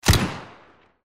Weapon SFX